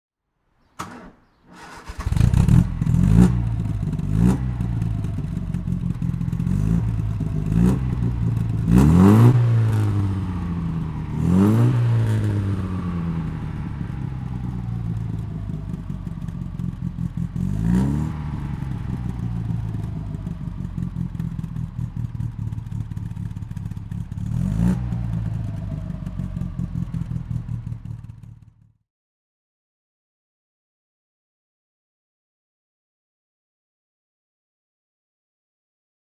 Motorsounds und Tonaufnahmen zu MG Fahrzeugen (zufällige Auswahl)
MGA Twin Cam (1959) - Starten und Leerlauf
MGA_Twin_Cam_1959.mp3